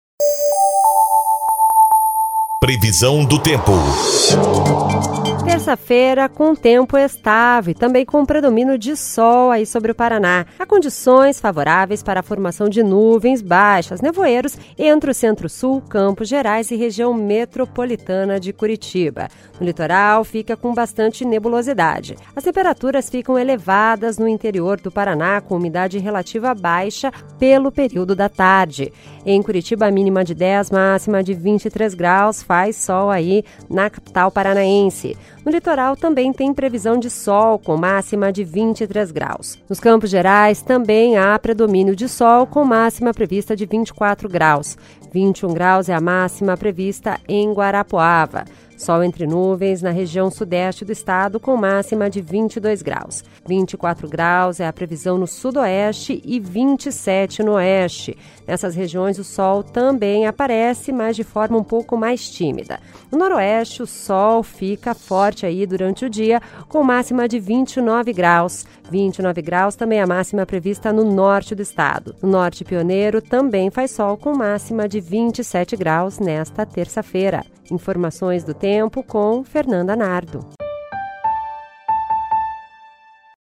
Previsão do Tempo (25/07)